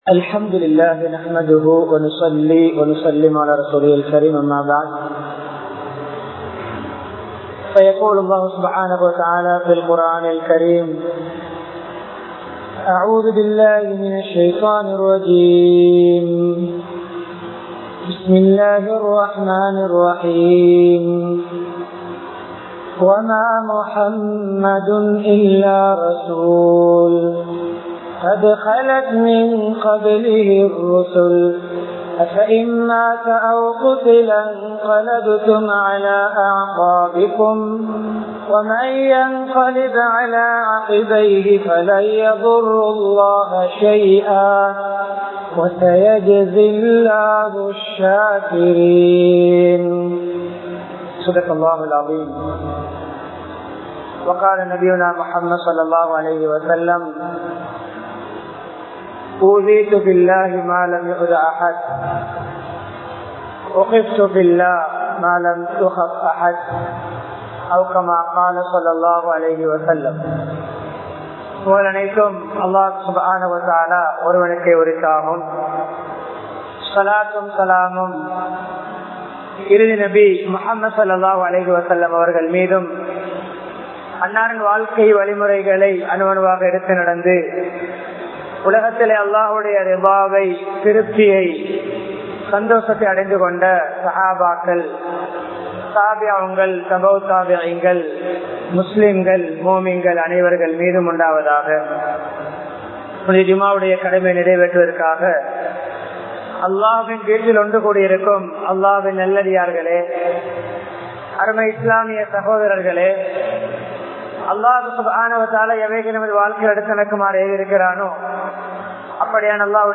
நபி(ஸல்) அவர்களின் இறுதி நாட்கள் | Audio Bayans | All Ceylon Muslim Youth Community | Addalaichenai
Avissawella, Town Jumuah Masjith